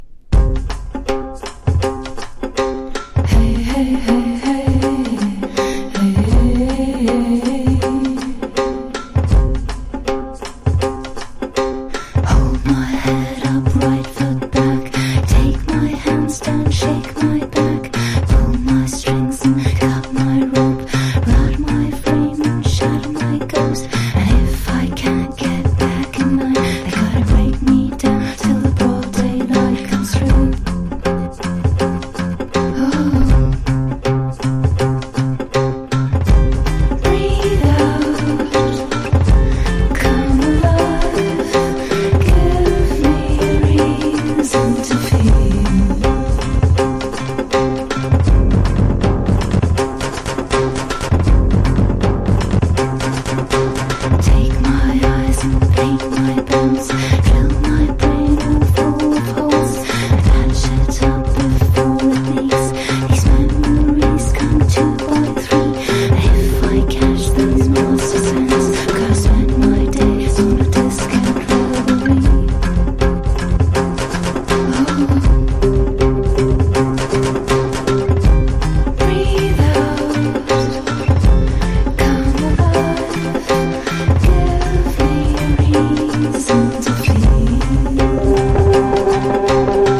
1. 00S ROCK >